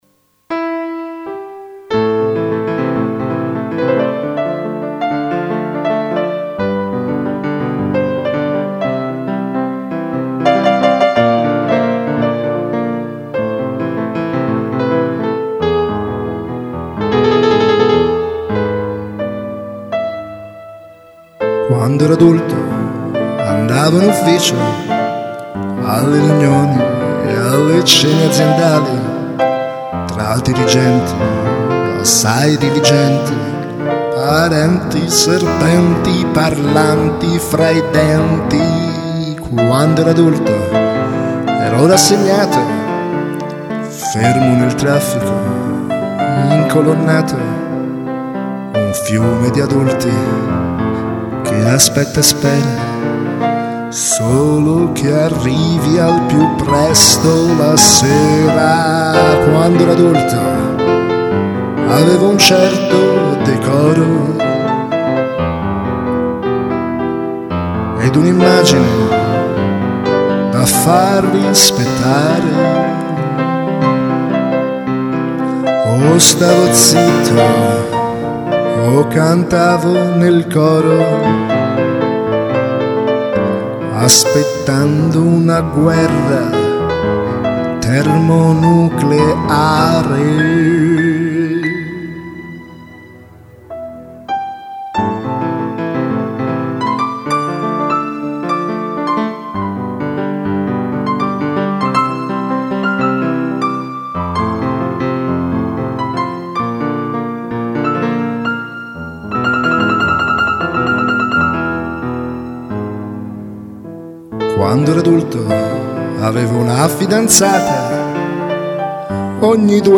Ogni tanto mi scappa una canzone un po' seria, e io non posso che lasciarla uscire... una ballata pianistica, un po' autobiografica e un po' no, col compiacimento di chi è riuscito a salvarsi da tutto questo.